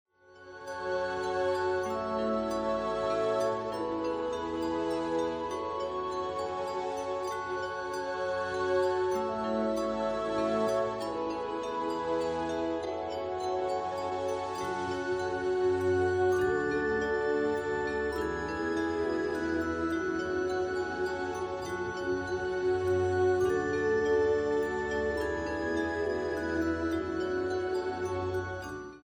Her style ranges between New Age and Electronic.
This CD is a live recording.